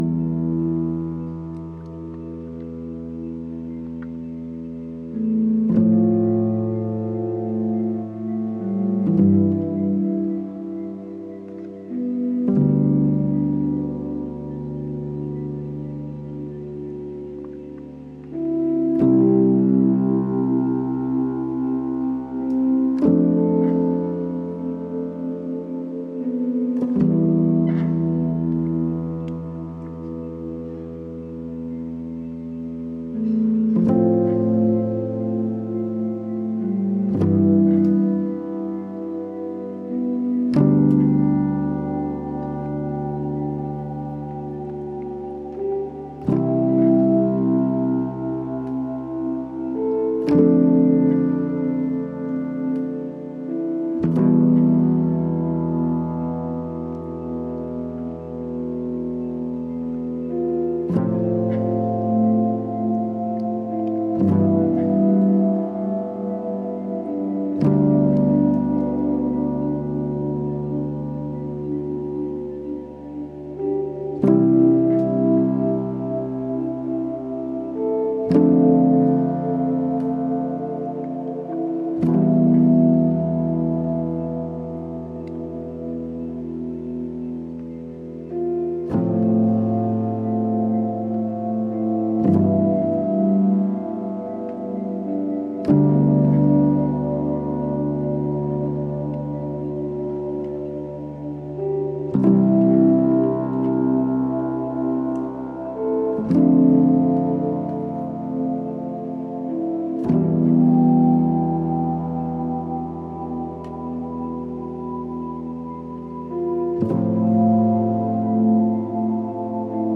Ambient Guitar